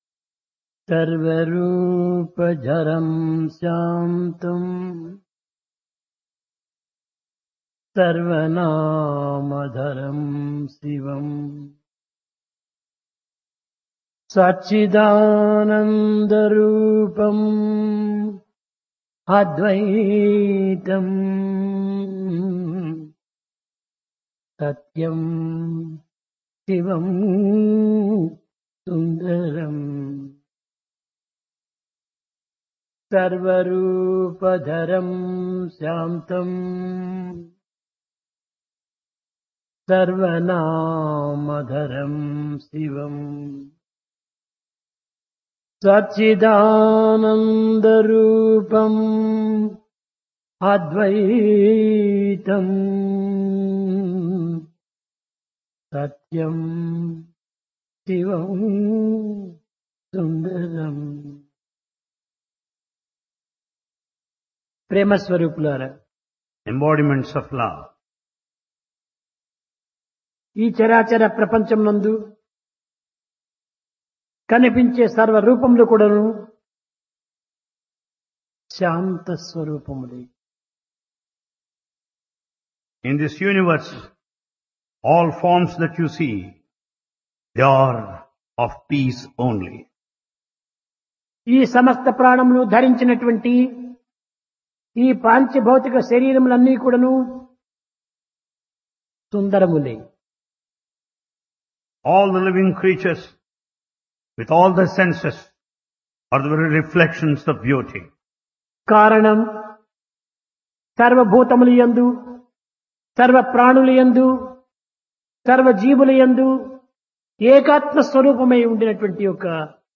Discourse
Place Prasanthi Nilayam